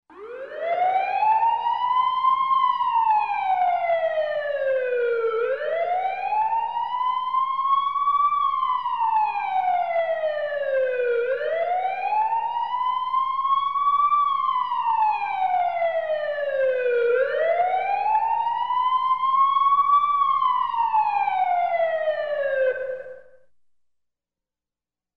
Sirens, Ambulance, Fire Truck, Police Car